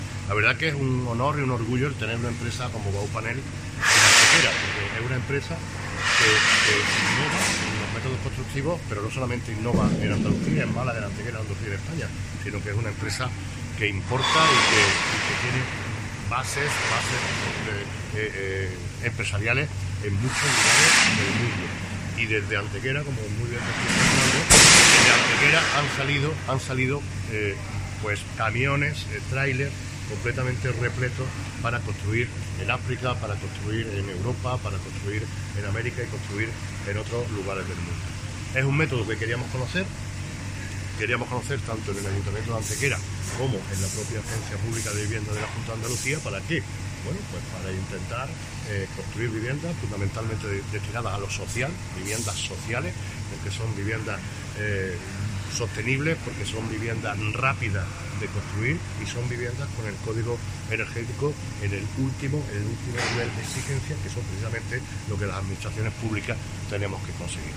El alcalde de Antequera, Manolo Barón, y el director general de la Agencia de Vivienda y Rehabilitación de Andalucía, Juan Carlos Del Pino, visitaban en el mediodía de este lunes 13 de enero las instalaciones de la empresa Baupanel en el Polígono Industrial de Antequera.
Cortes de voz